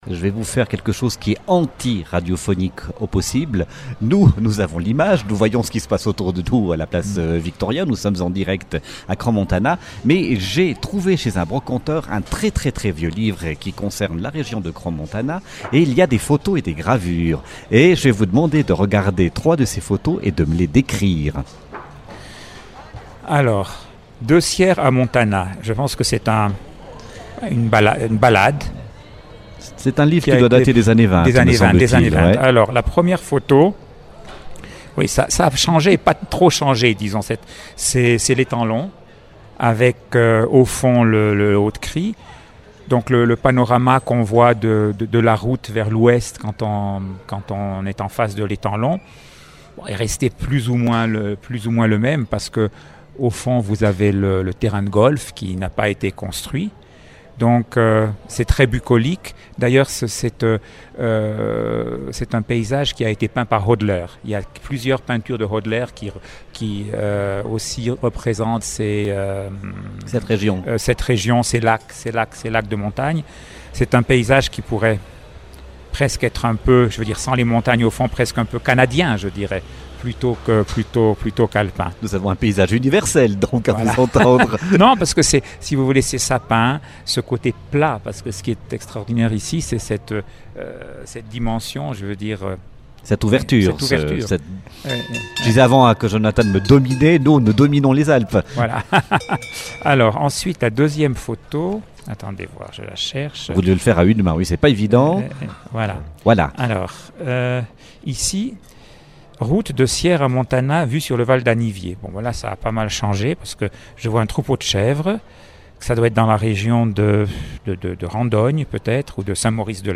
Radio Crans-Montana était en direct du marché à Crans-Montana le 31 juillet. L'occasion de rencontrer de nombreux acteurs de la station.